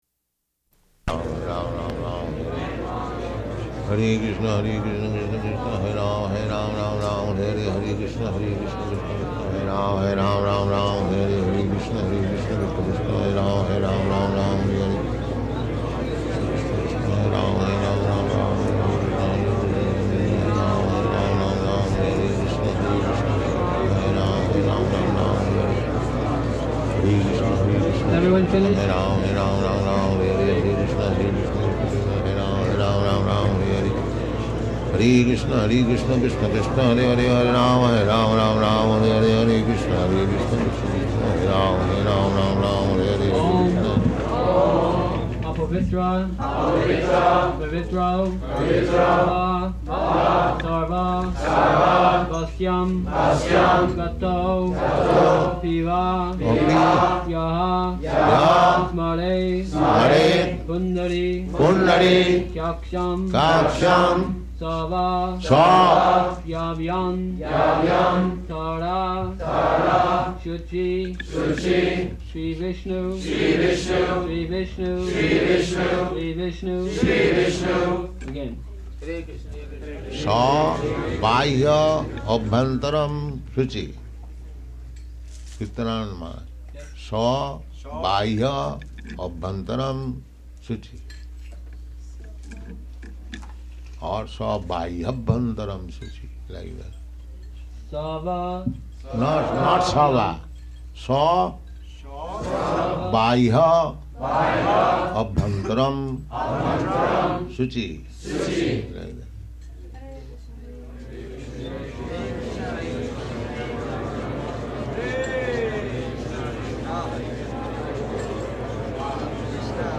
-- Type: Initiation Dated: September 1st 1972 Location: New Vrindavan Audio file